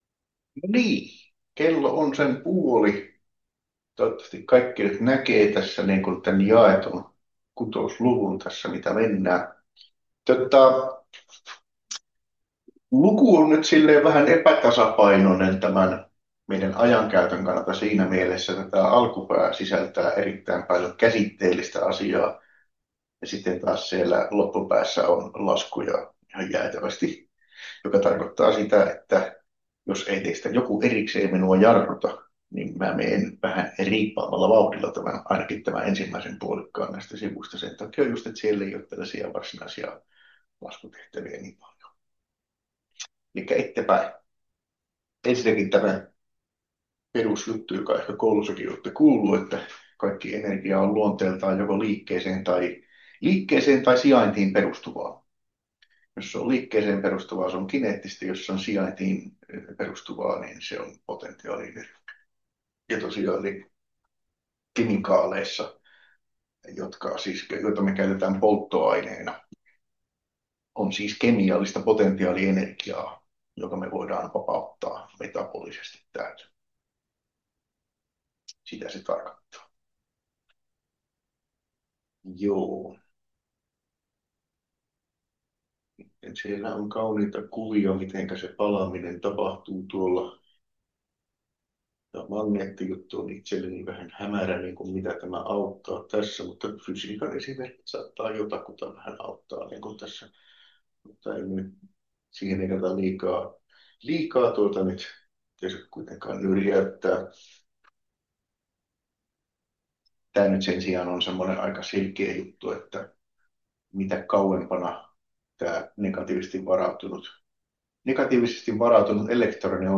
KEMP1111 luento 6 osa 1 — Moniviestin